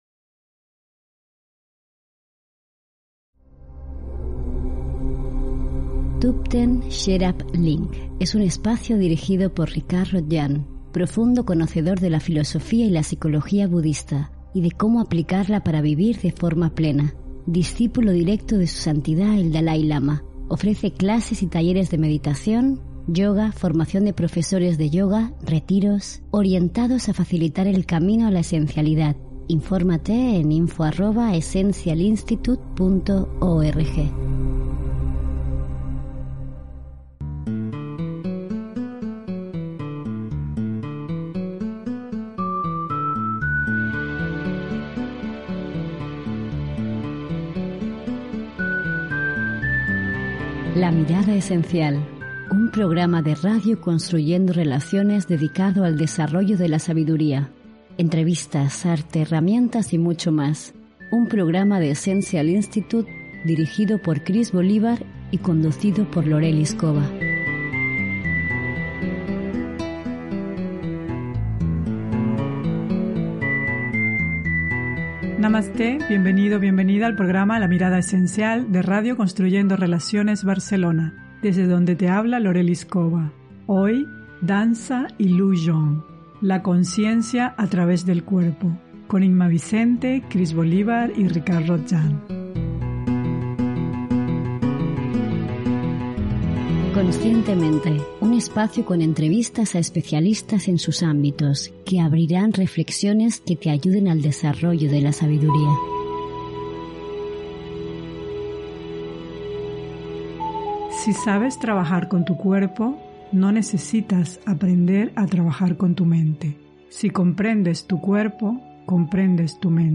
RADIO "LA MIRADA ESENCIAL" - DANZA Y LU JONG: LA CONSCIENCIA A TRAVÉS DEL CUERPO - 31-7-19